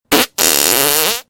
Play Jimi Fart 1 - SoundBoardGuy
jimi-fart-1.mp3